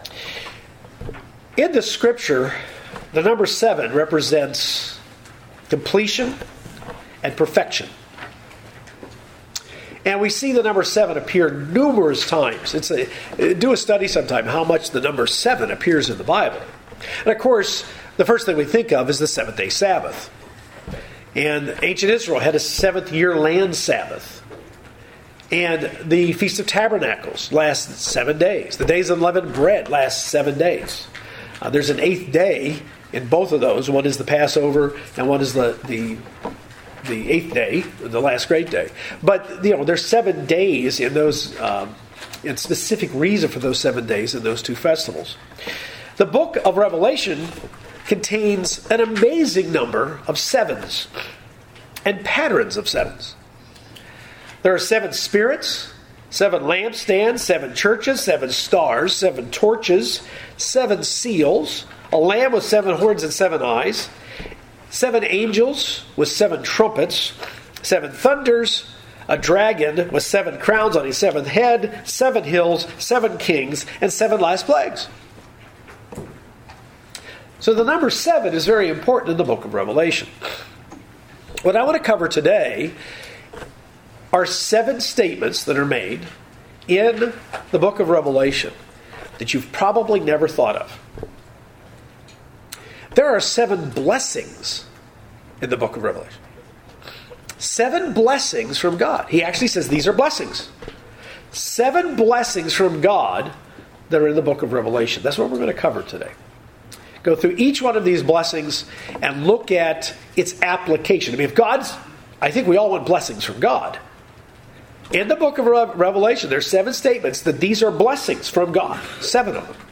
The Bible has a lot of numbers mentioned in it, with one of the most important ones being the number seven. This sermon looks at the seven blessings found in the book of Revelation.